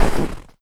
STEPS Snow, Run 15.wav